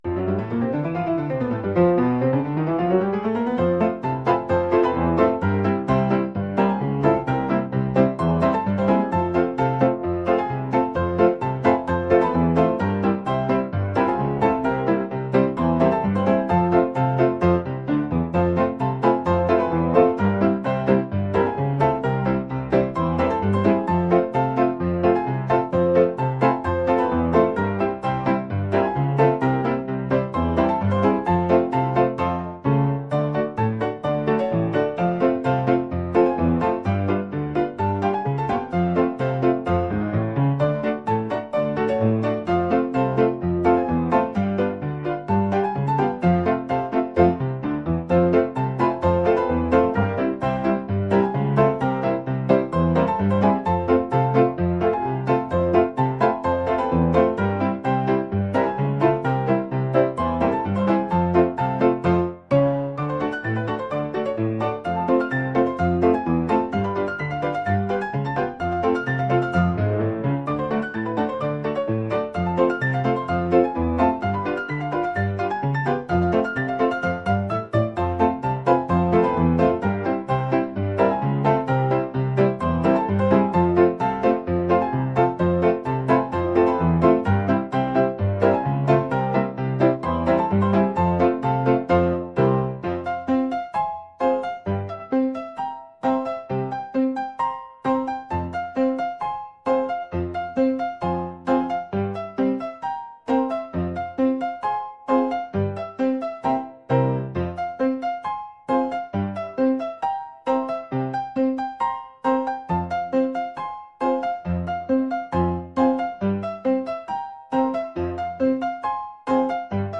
energetic | jazz